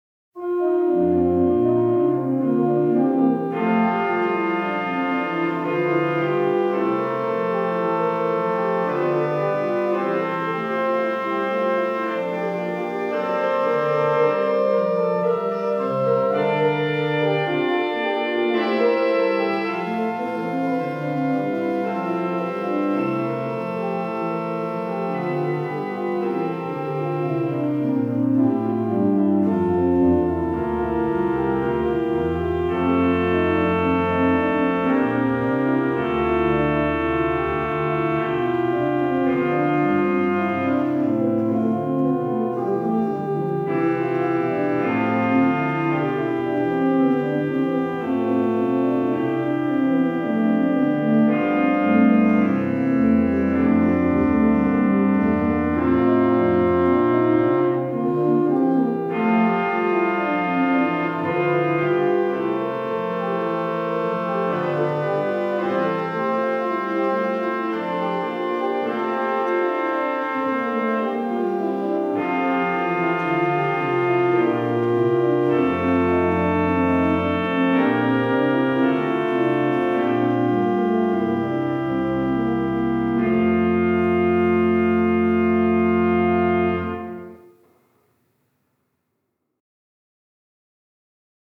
In the music there is a canon; a repeated melody or round; just like Frere Jacques. One voice goes four times as fast as the melody and Bach specifies the trumpet stop for one of the melodies.